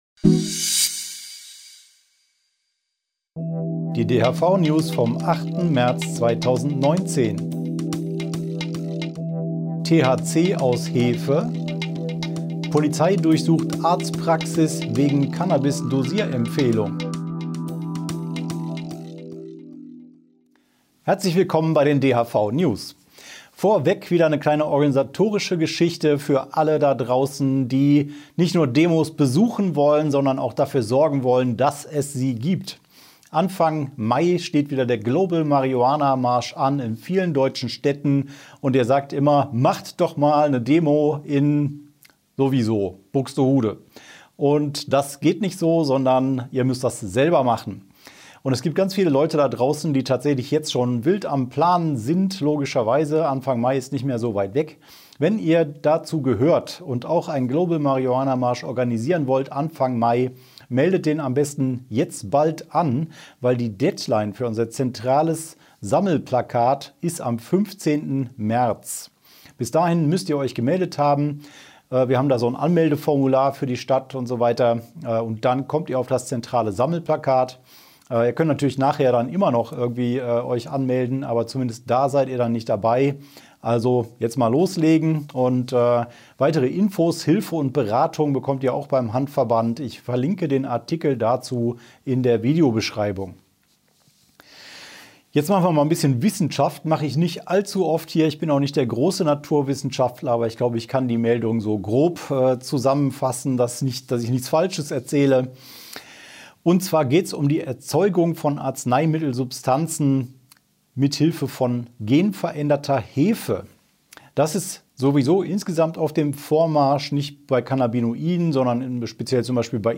DHV-Video-News #198 Die Hanfverband-Videonews vom 08.03.2019 Die Tonspur der Sendung steht als Audio-Podcast am Ende dieser Nachricht zum downloaden oder direkt hören zur Verfügung.